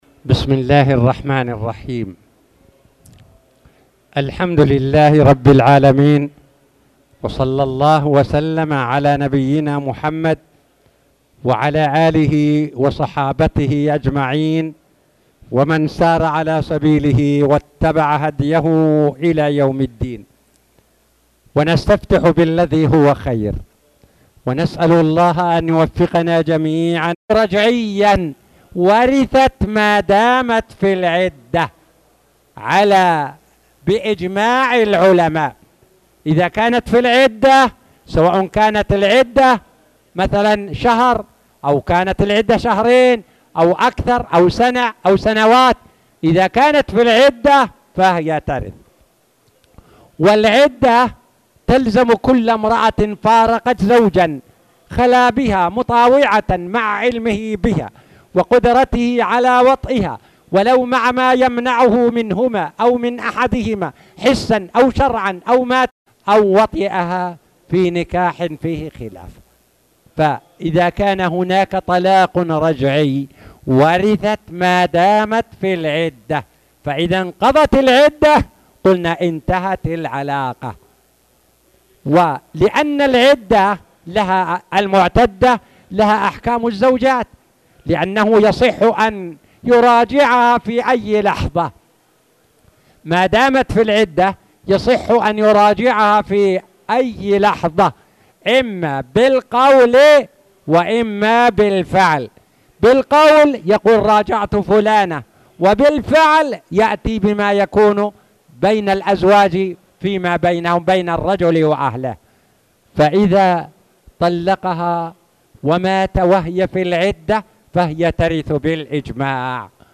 تاريخ النشر ١٨ ذو القعدة ١٤٣٧ هـ المكان: المسجد الحرام الشيخ